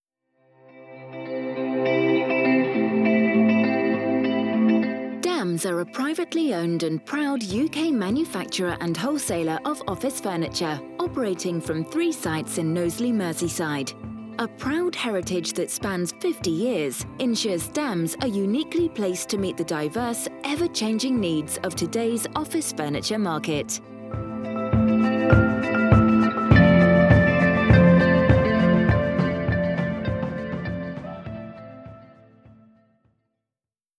I can arrange voice over recordings for music on hold and IVR projects for your business.
I only use industry standard voice artists for my voice overs to create a professional finished product.
Messages On Hold Demo 1